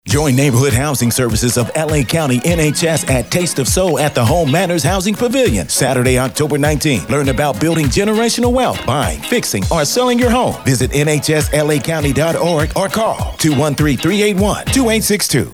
NHS Taste of Soul Radio Spot Promos